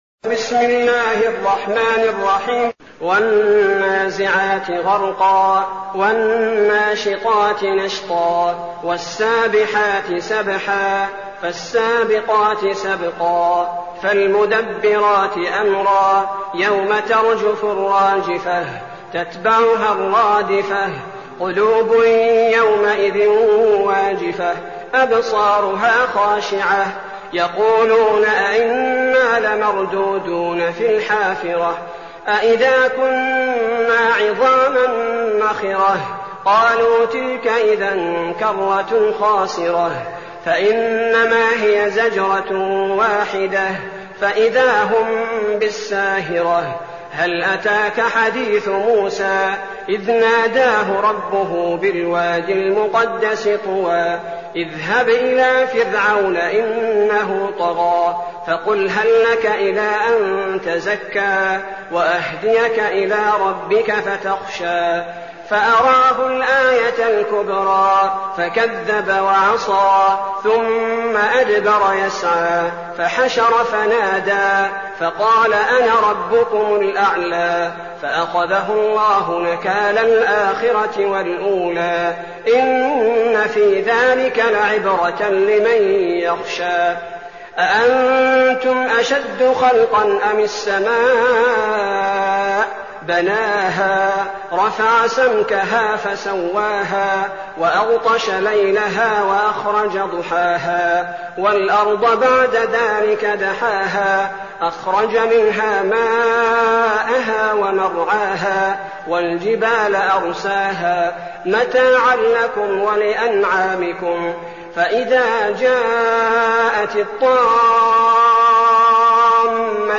المكان: المسجد النبوي الشيخ: فضيلة الشيخ عبدالباري الثبيتي فضيلة الشيخ عبدالباري الثبيتي النازعات